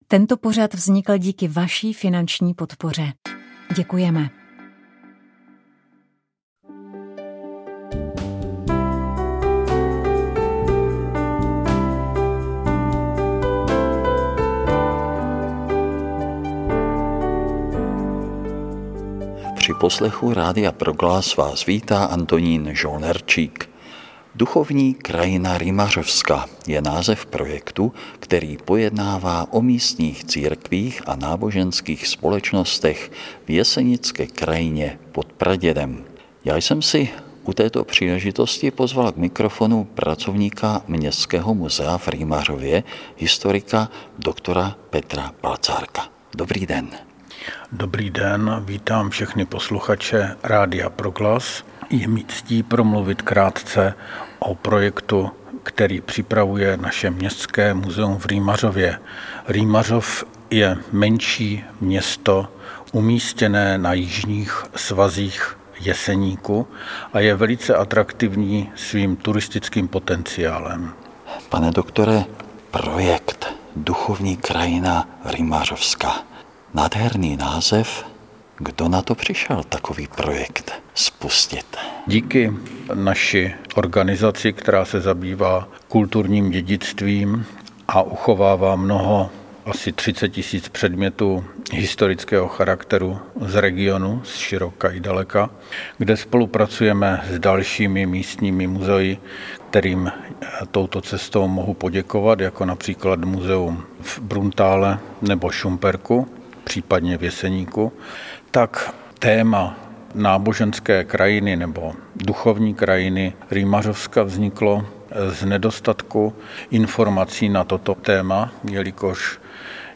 S mikrofonem jsme navštívili tu druhou, kde je k vidění řada různých hraček. Najdete zde autíčka, parní stroje, promítačky.